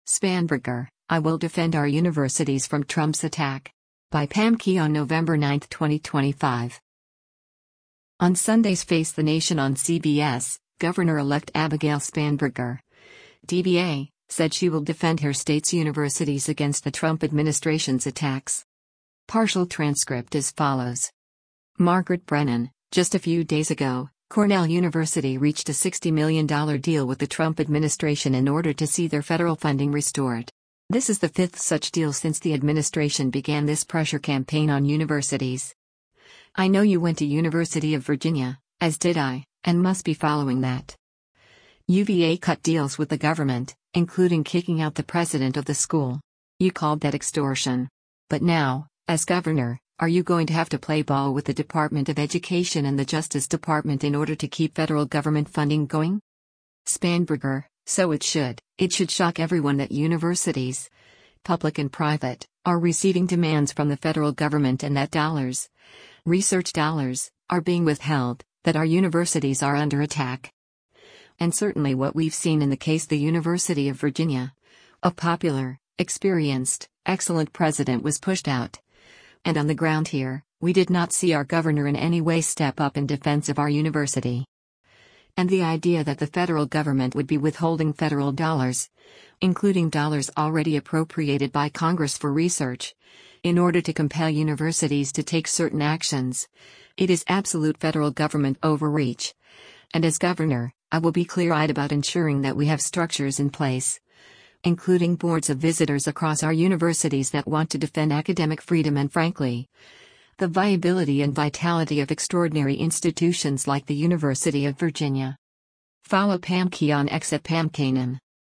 On Sunday’s “Face the Nation” on CBS,  Gov.-elect Abigail Spanberger (D-VA) said she will defend her state’s universities against the Trump administration’s attacks.